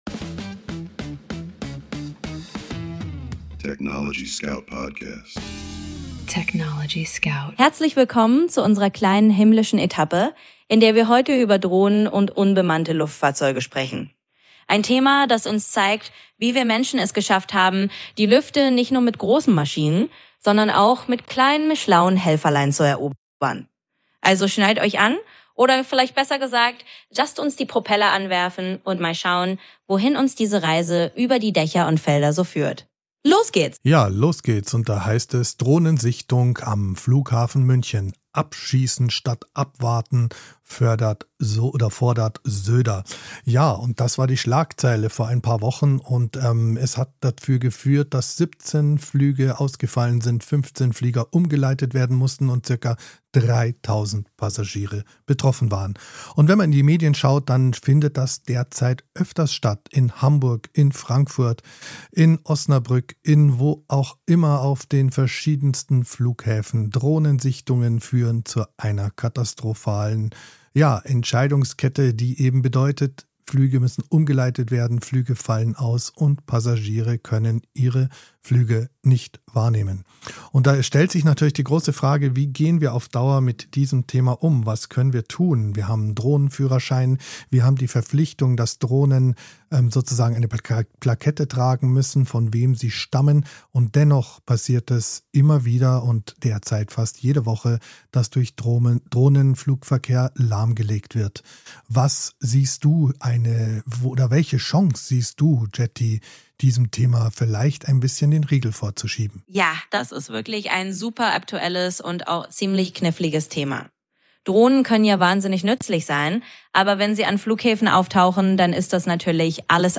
Unsere Gespräche sind voller Aha-Momente, Denkanstöße und